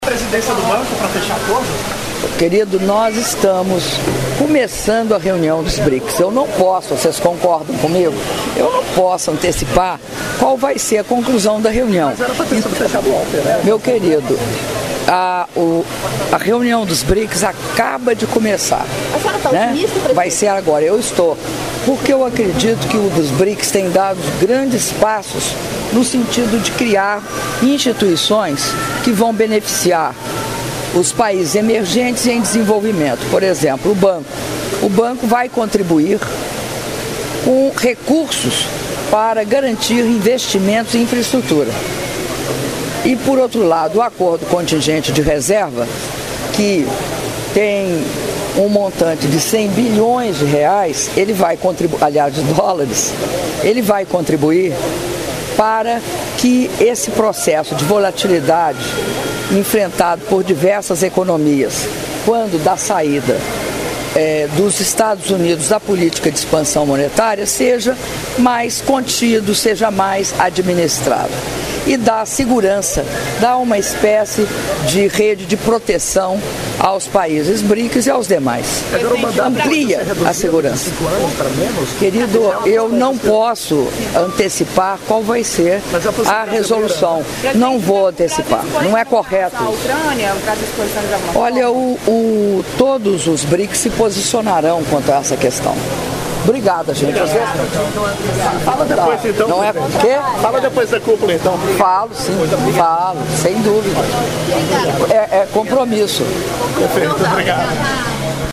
Áudio da entrevista coletiva concedida pela Presidenta da República, Dilma Rousseff, antes da Primeira Sessão Privada de Trabalho da VI Cúpula dos BRICS - Fortaleza/CE (01min49s) — Biblioteca